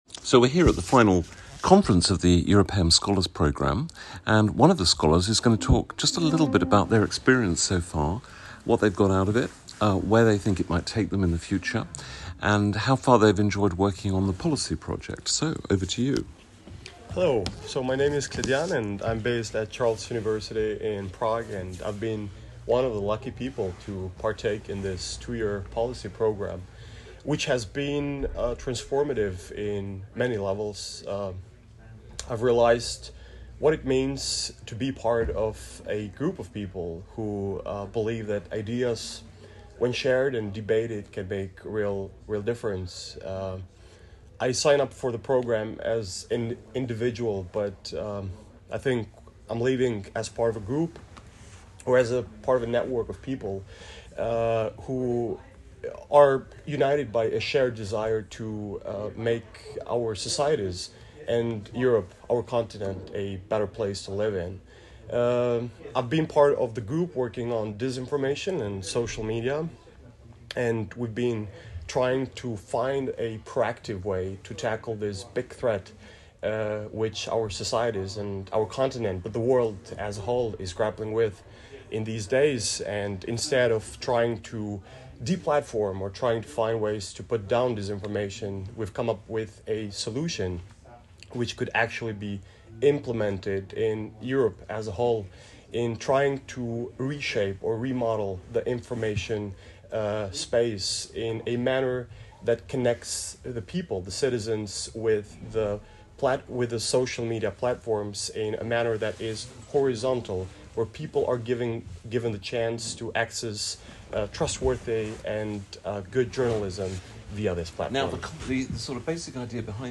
The interview was conducted on 15 October 2025 during the Scholars’ policy conference in Brussels.